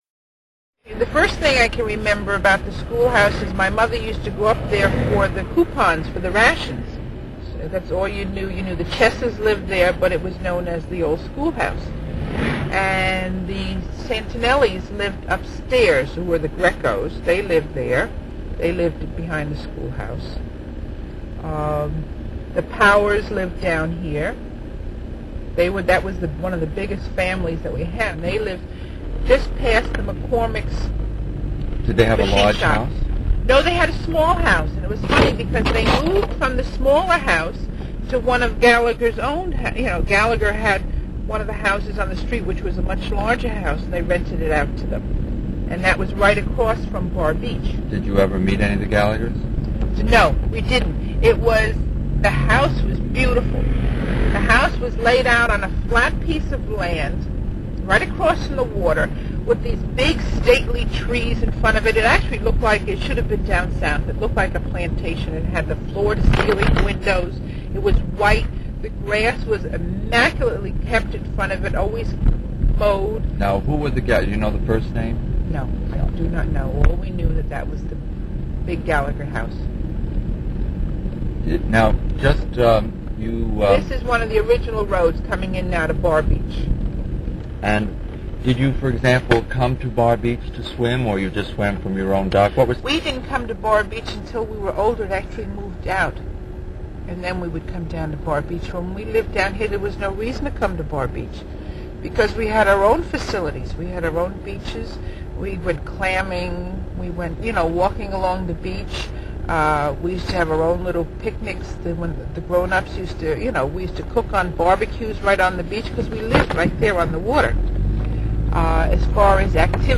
The following interview is one of a series of tape-recorded memoirs in the Port Washington Public Library Community Oral History Program.